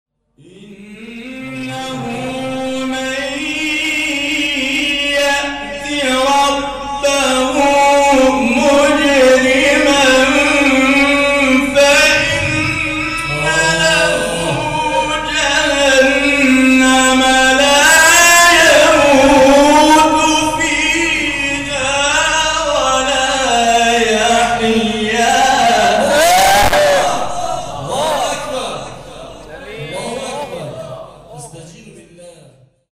گروه فعالیت‌های قرآنی: جدیدترین مقاطع صوتی تلاوت شده توسط قاریان ممتاز کشور را می‌شنوید.